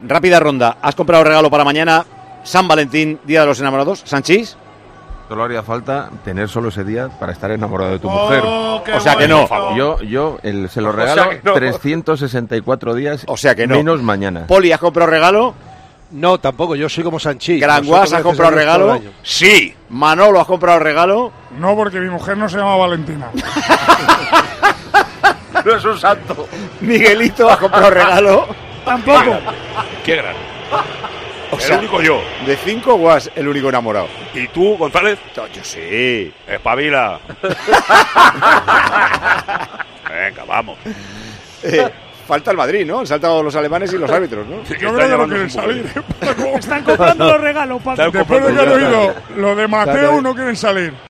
El ataque de risa de Paco González al escuchar lo que Manolo Lama hará por San Valentín
Paco González no pudo evitar estallar de risa al escuchar la respuesta de Manolo Lama cuando el director del programa le hizo la pregunta.